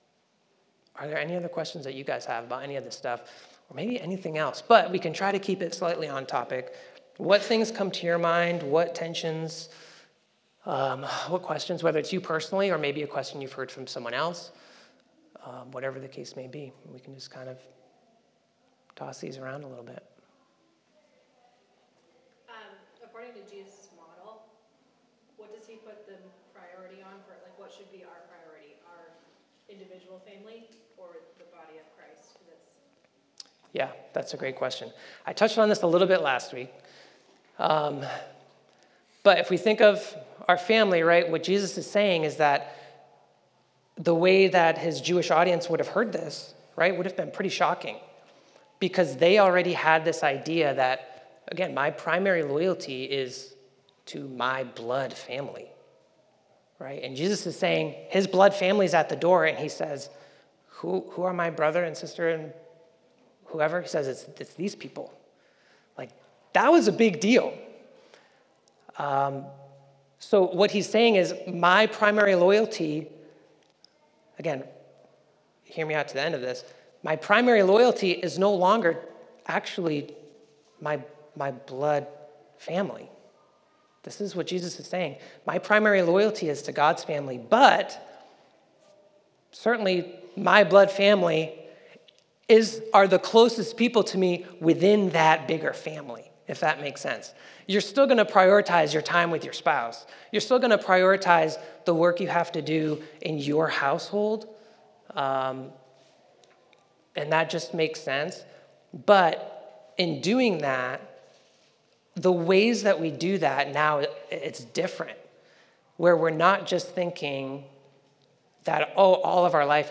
Sermon Audio
The church asked four questions. You cannot hear the questions being asked so they are listed below with the corresponding timestamp.